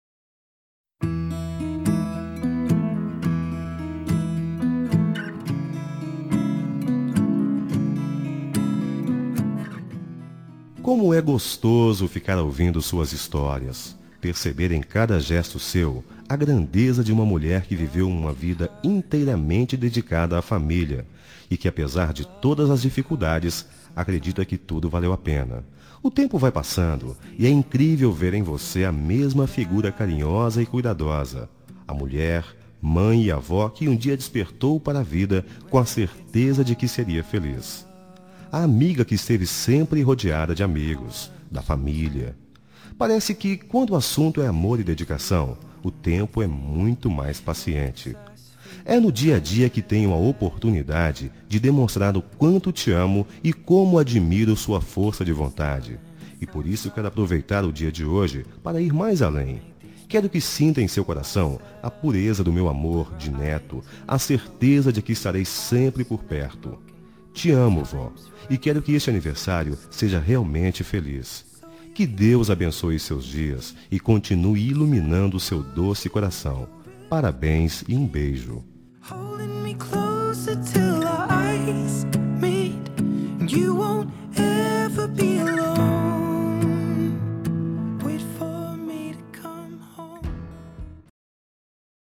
Telemensagem de Aniversário de Avó – Voz Masculina – Cód: 2069